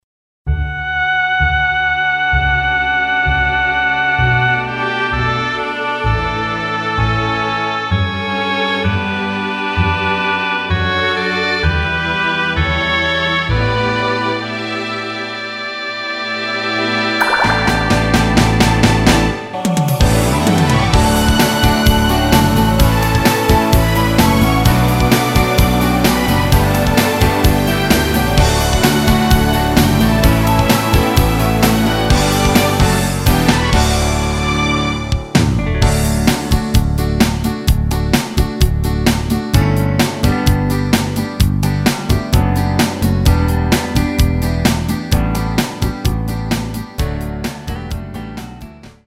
-2)MR 입니다.
원곡의 보컬 목소리를 MR에 약하게 넣어서 제작한 MR이며